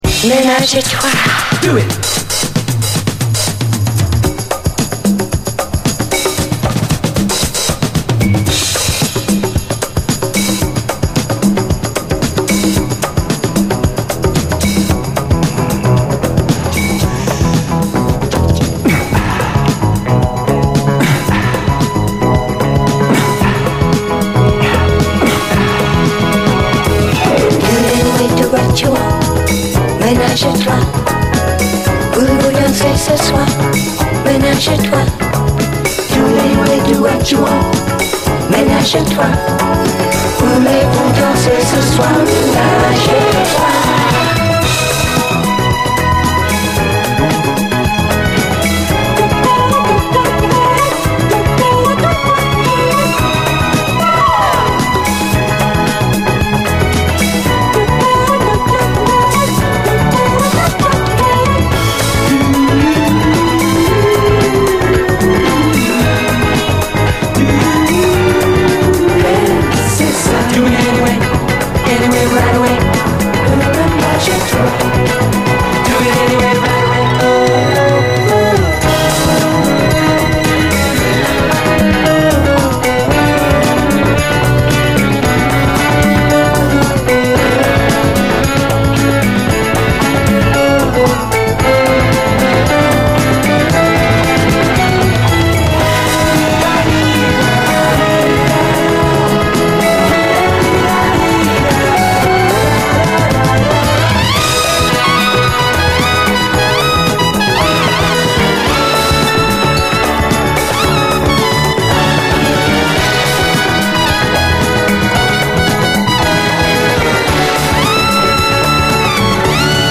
SOUL, 70's～ SOUL, DISCO, 7INCH
イントロにパーカッシヴなB-BOYブレイクのあるシングル・ヴァージョン！